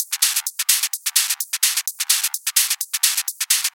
VEH1 Fx Loops 128 BPM
VEH1 FX Loop - 16.wav